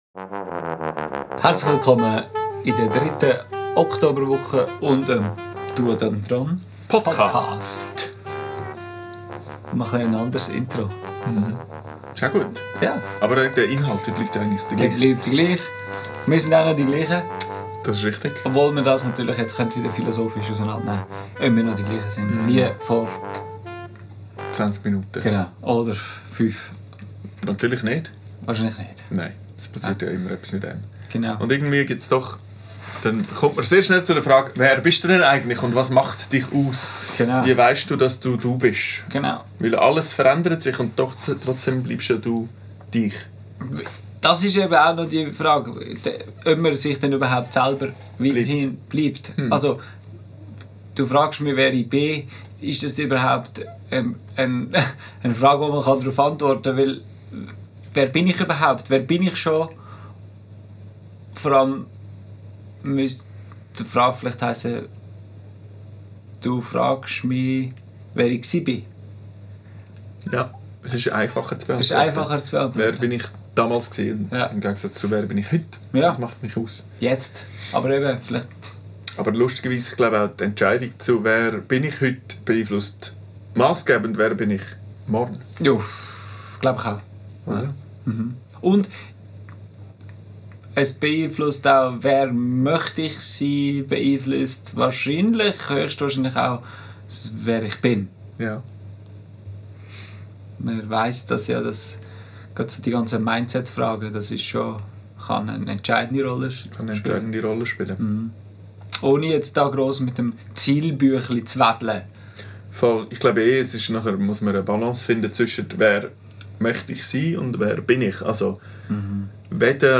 Aufgenommen am 17.09.2025 im Atelier